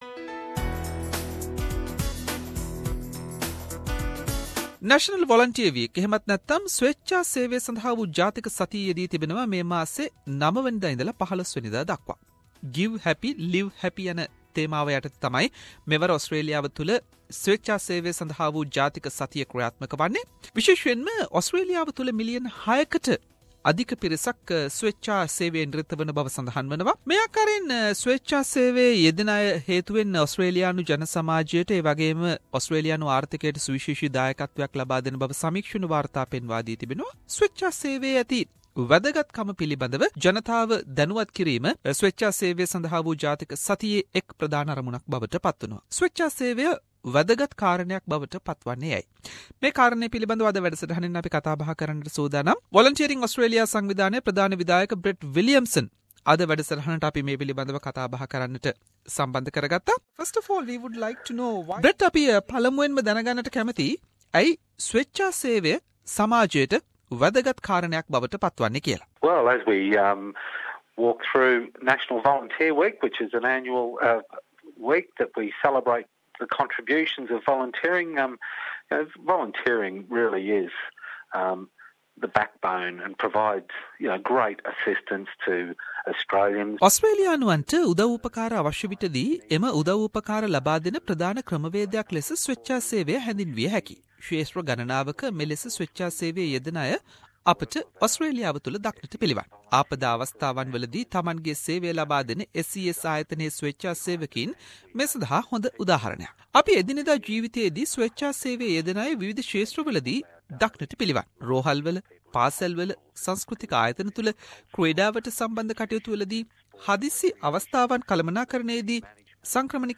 Special interview